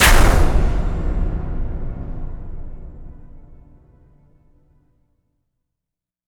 LC IMP SLAM 9C.WAV